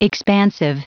Prononciation du mot expansive en anglais (fichier audio)
Prononciation du mot : expansive